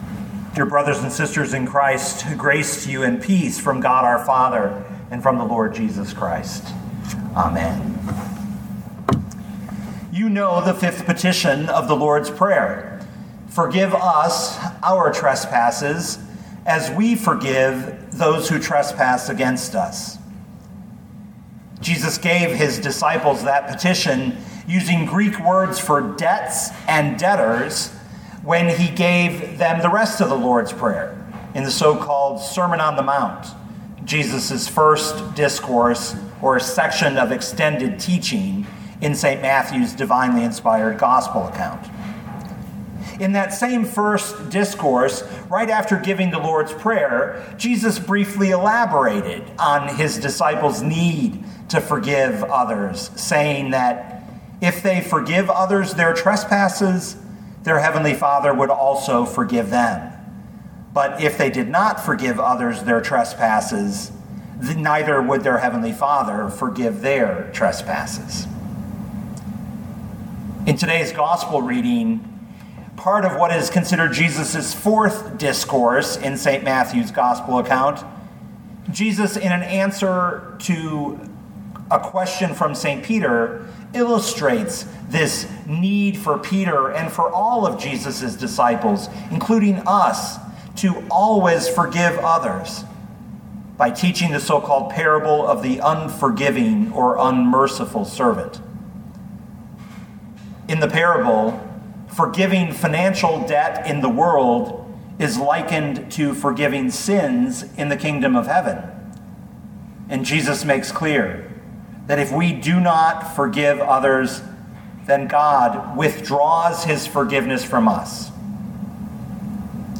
2020 Matthew 18:21-35 Listen to the sermon with the player below, or, download the audio.